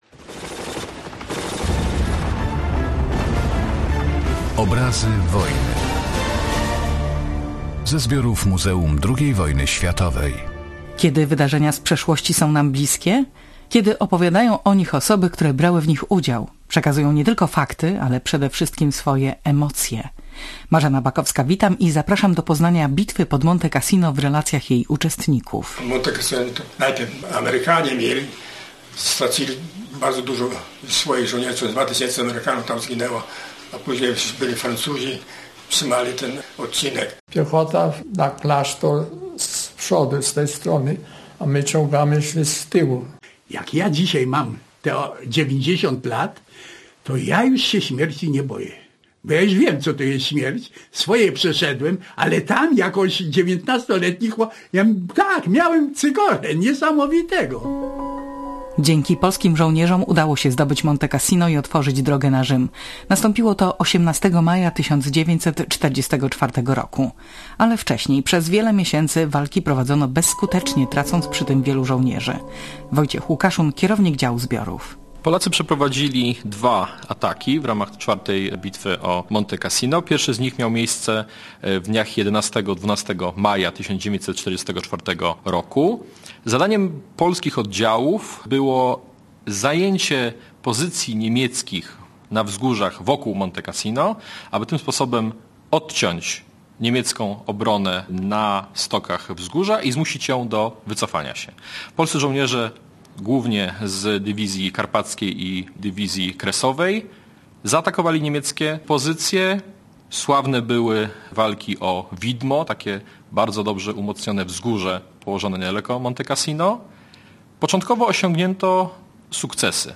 Świadkowie wydarzeń opisują wydarzenia ze swojej perspektywy, mówią o towarzyszących im emocjach. Ich relacje pochodzą ze zbiorów Muzeum II Wojny Światowej.